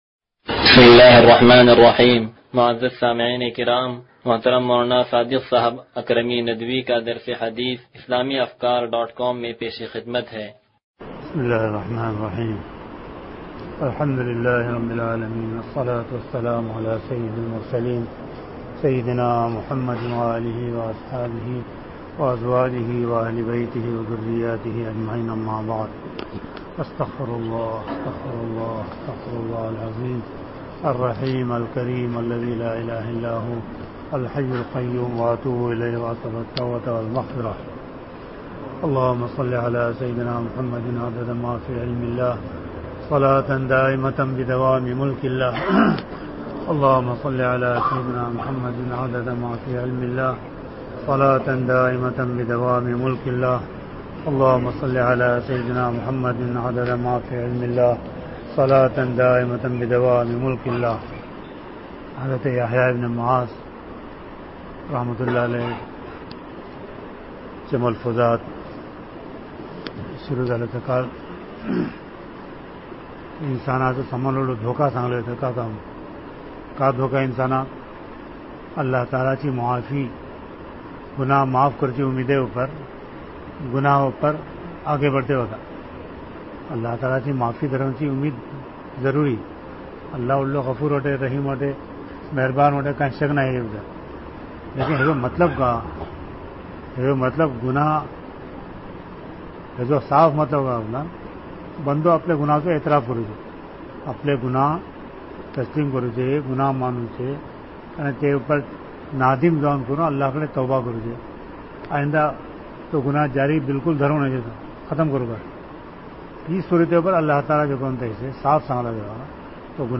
درس حدیث نمبر 0176